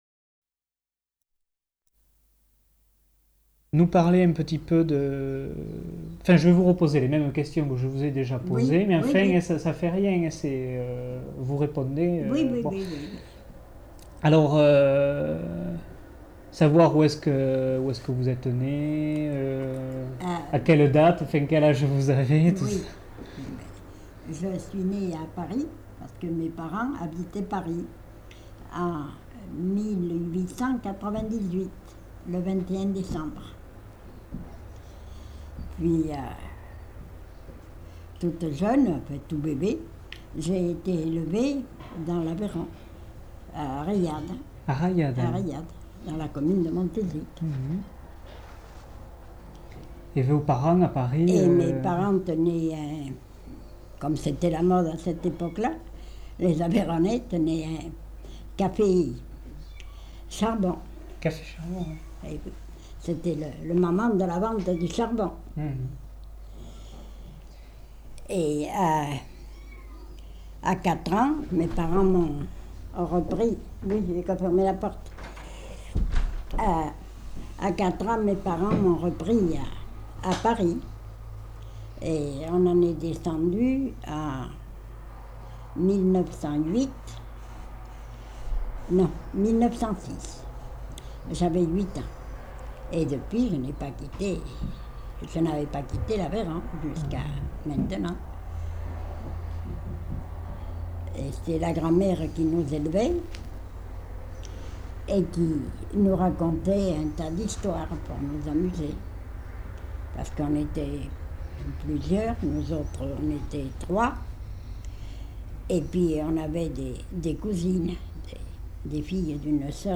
Lieu : Tournay
Genre : récit de vie